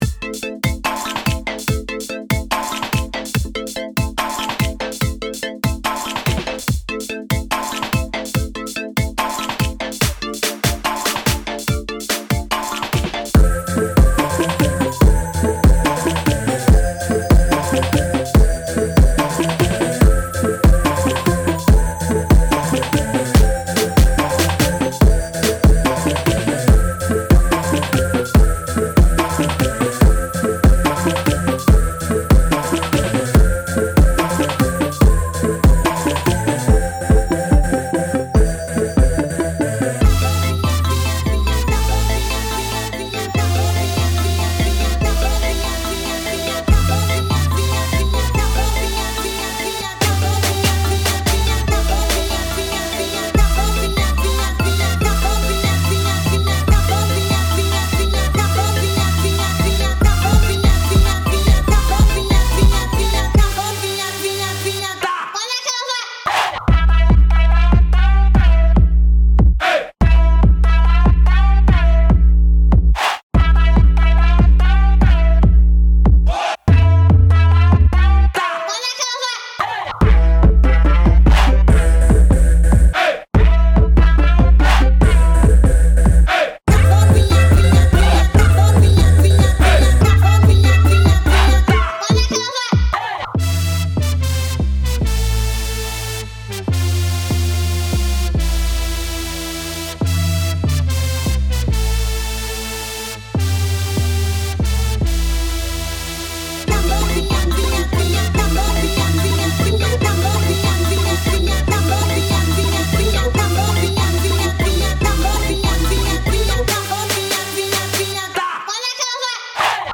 超过50种光滑的热带空间震动，提醒您您身处黑客帝国。
每个声音手都经过精心设计，可以在温暖的夜空下生活在自己的水世界中。